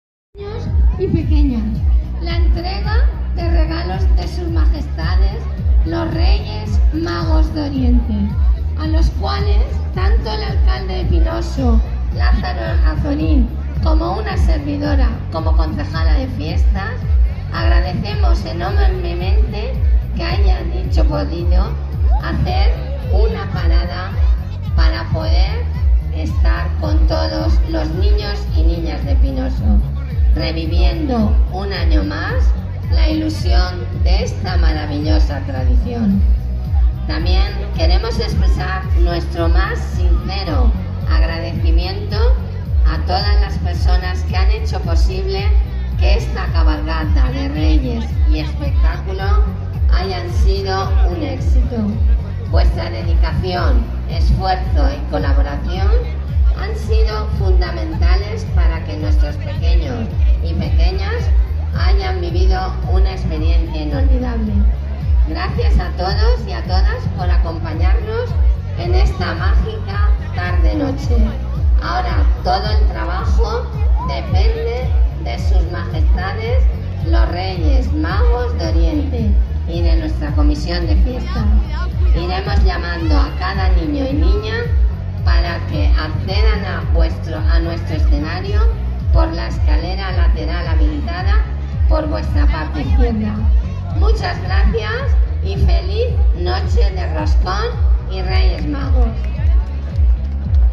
Elisa Santiago, Edil de Fiestas.